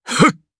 DarkKasel-Vox_Casting1_jp.wav